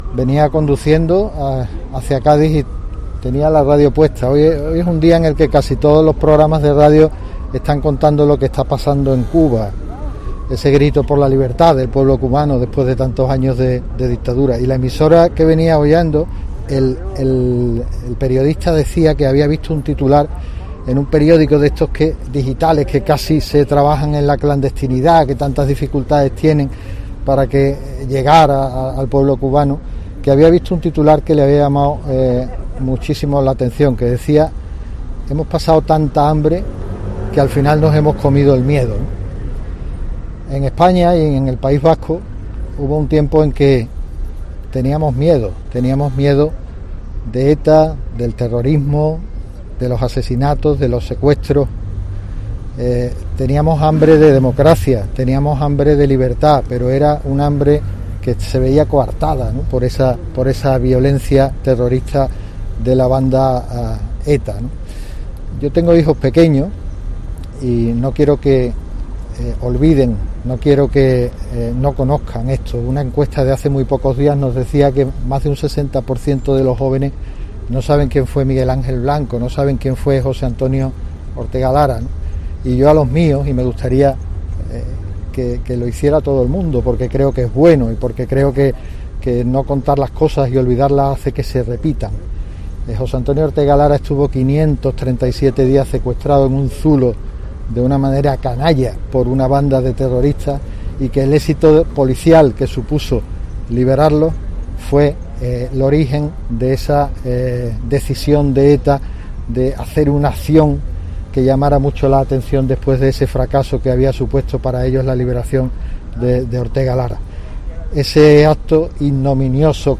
Un acto ante el Monumento de las Cortes rememora al concejal de Ermua víctima del cruento asesinato perpetrado por ETA que sacudió a España de modo crucial